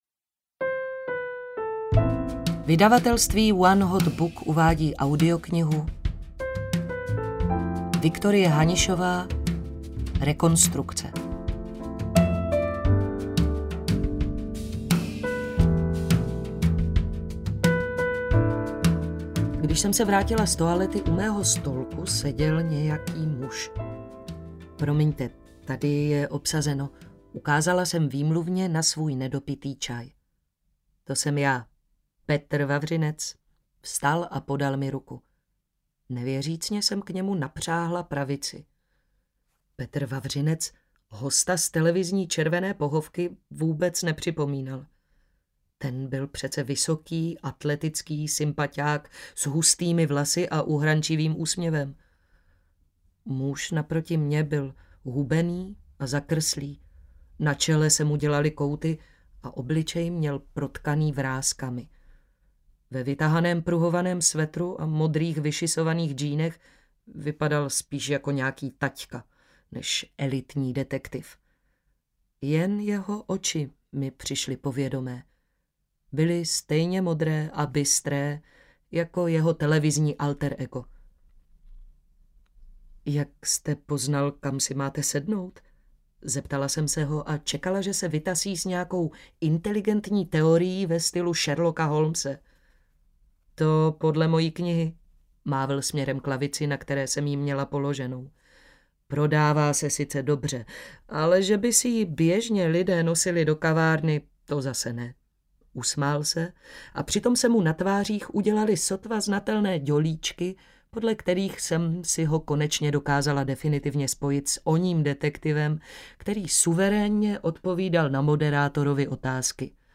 Rekonstrukce audiokniha
Ukázka z knihy